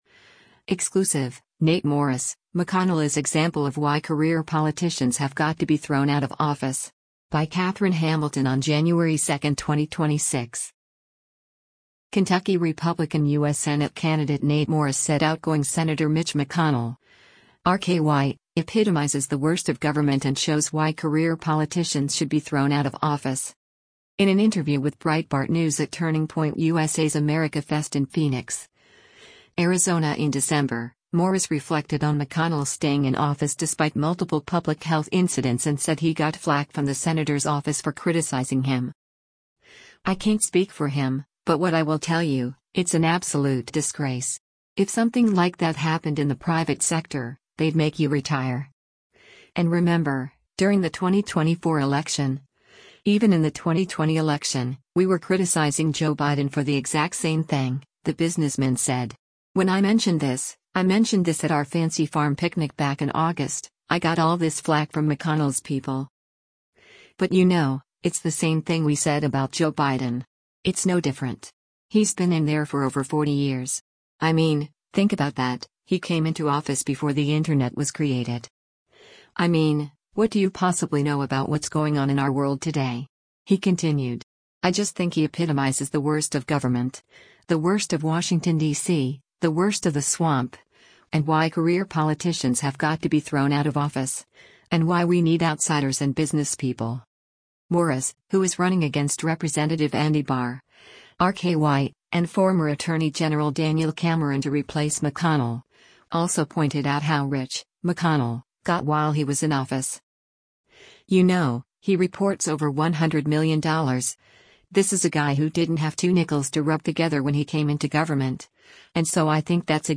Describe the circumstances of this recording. In an interview with Breitbart News at Turning Point USA’s AmericaFest in Phoenix, Arizona in December